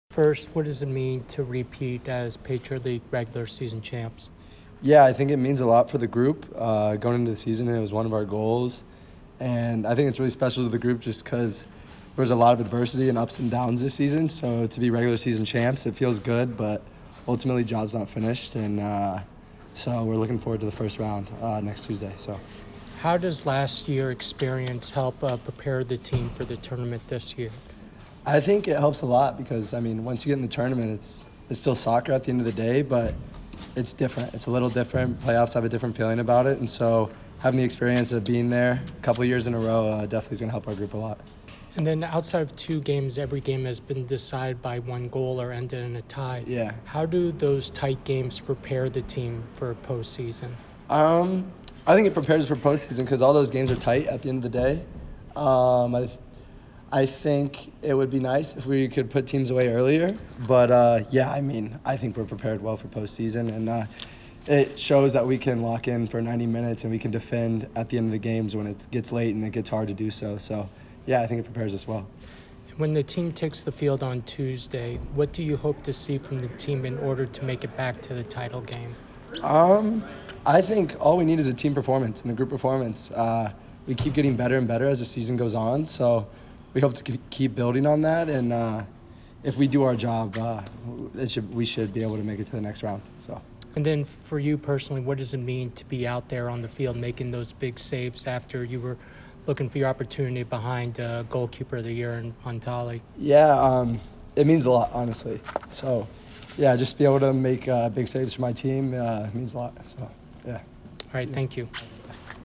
Holy Cross Postgame Interview